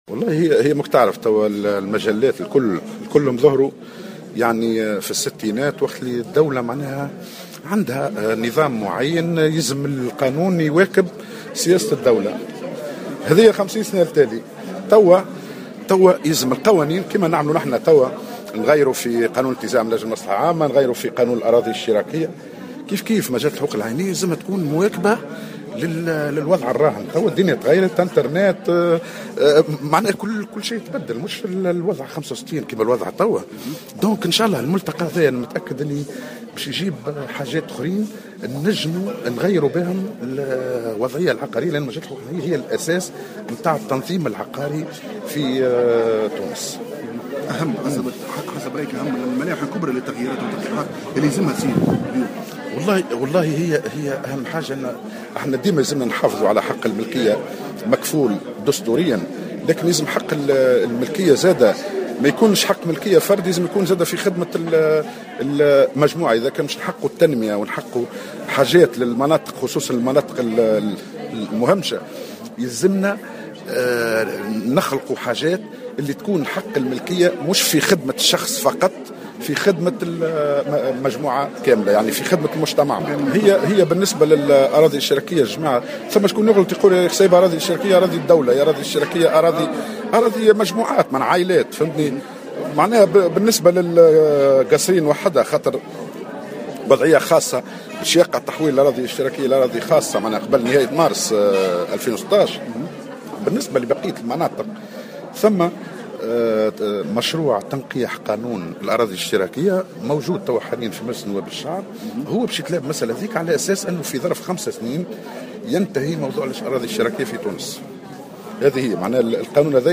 Le ministre des domaines de l'Etat et des affaires foncières, Hatem El Echi, a indiqué dans une déclaration au micro du correspondant de Jawhara FM ce mercredi 10 février 2016, que les affectations des terrains collectifs au gouvernorat de Kasserine seront changées en privées avant la fin du mois de mars 2016.